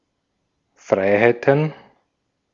Ääntäminen
Ääntäminen Tuntematon aksentti: IPA: /ˈfʀaɪ̯haɪ̯tn̩/ IPA: /ˈfʀaɪ̯haɪ̯tən/ IPA: /ˈfʁaɪ̯ .haɪ ̯.tən/ Haettu sana löytyi näillä lähdekielillä: saksa Käännöksiä ei löytynyt valitulle kohdekielelle. Freiheiten on sanan Freiheit monikko.